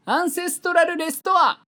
呪文 魔法 ボイス 声素材 – Magic Spell Voice